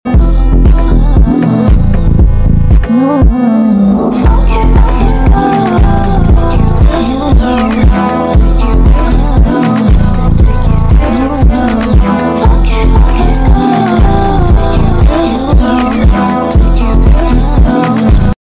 meow sound effects free download